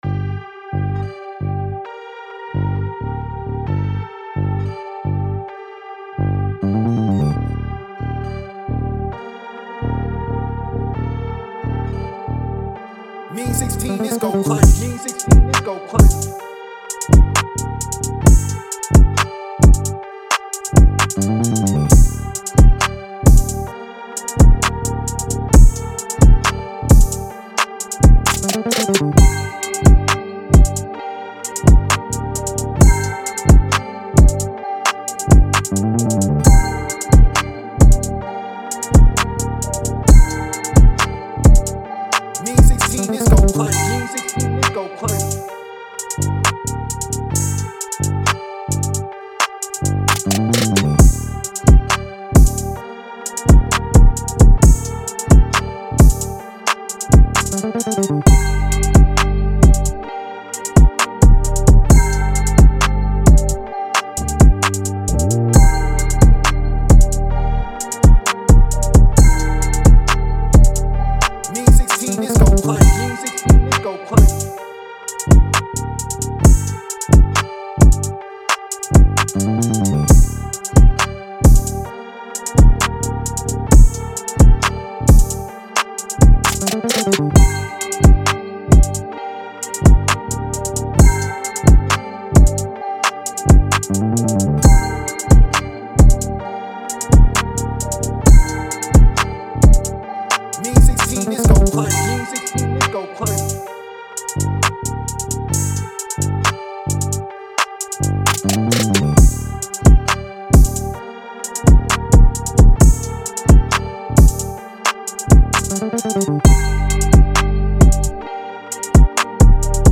G-Min 132-BPM